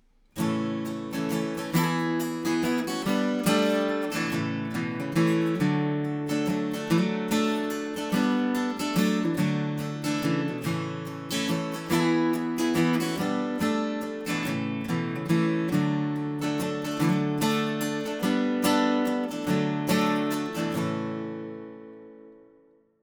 Guitare électro-acoustique :
Rythmique avec microphone externe et pickup interne :